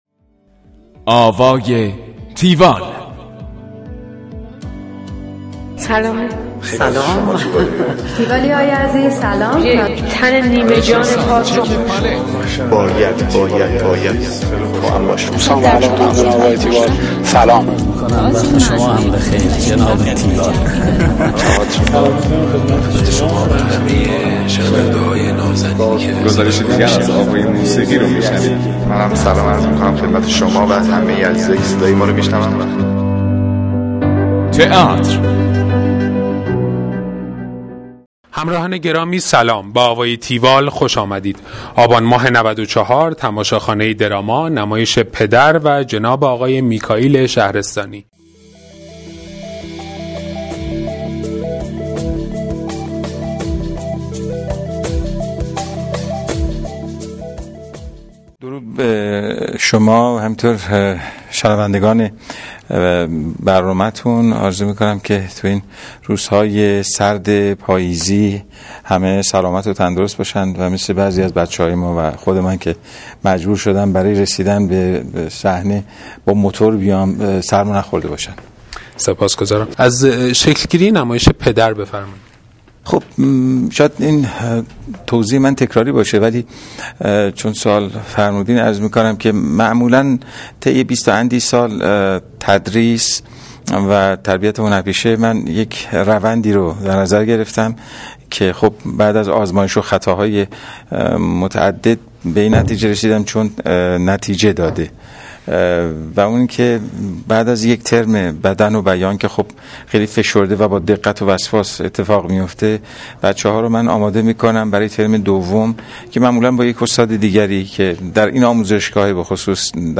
گفتگوی تیوال با میکائیل شهرستانی
گفتگوی تیوال با میکائیل شهرستانی / کارگردان.